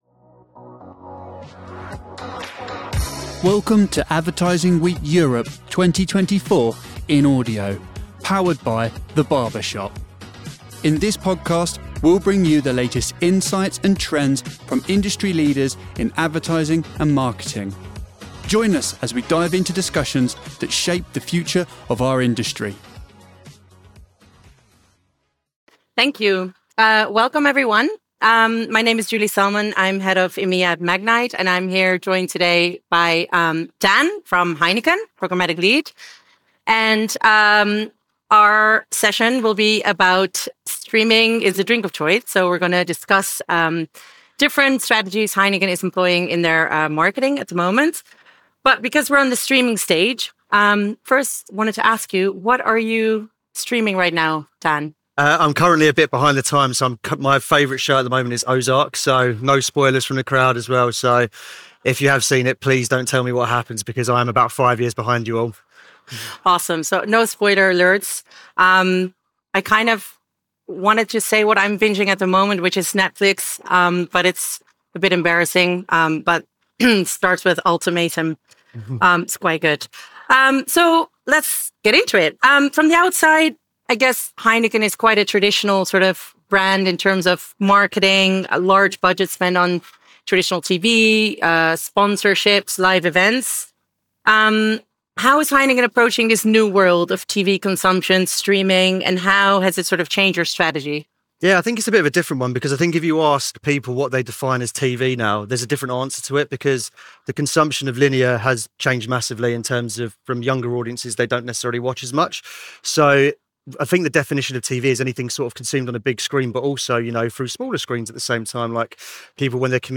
Explore the dynamic world of streaming media with insights from Magnite and Heineken. This fireside chat delves into how streaming is reshaping media consumption and influencing brand strategies.